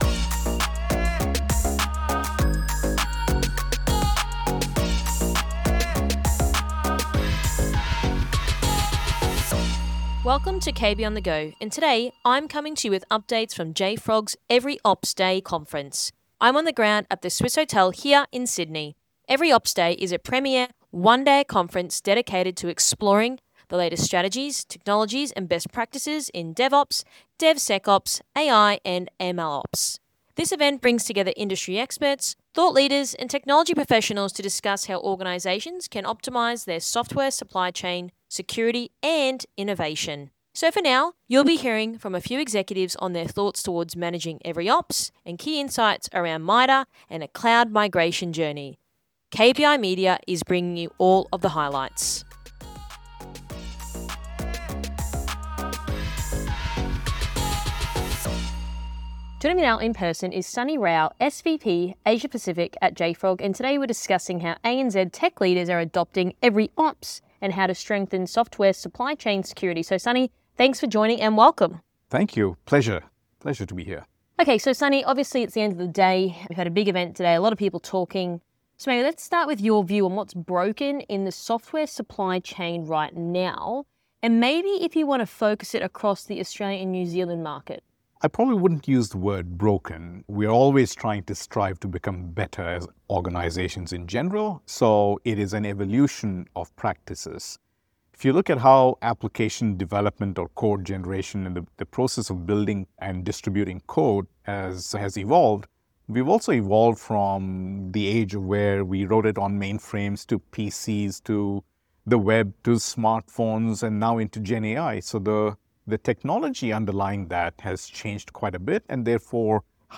From JFrog EveryOps Day ’25 Sydney